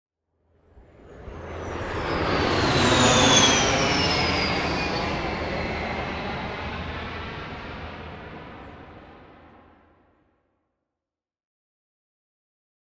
Airplane Avro Vulcan overhead engine far doppler jet